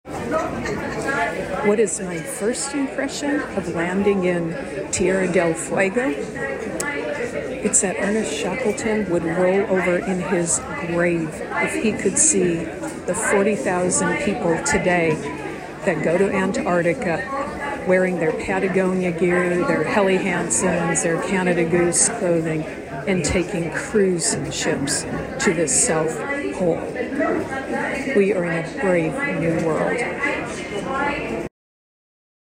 Armed with her trusty iPhone and a list of questions and topics for discussion,
23-008-First-Impressions-of-Tierra-de-lFuego.mp3